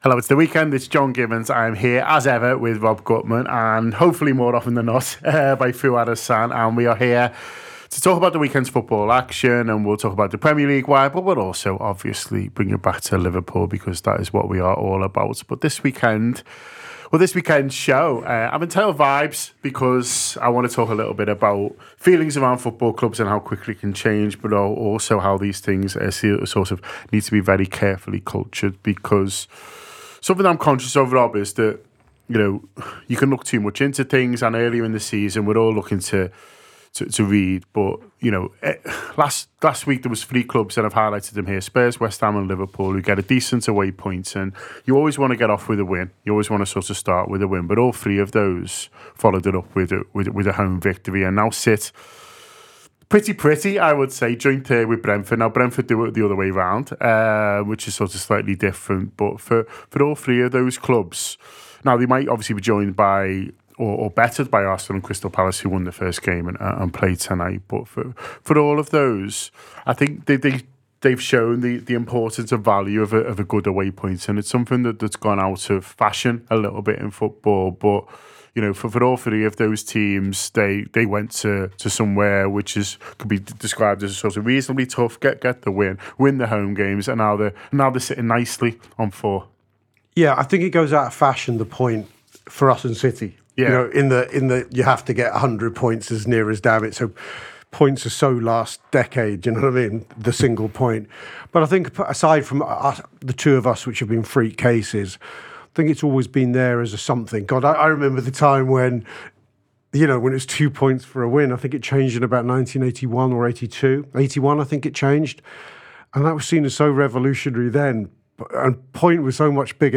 Below is a clip from the show – subscribe for more Premier League vibes…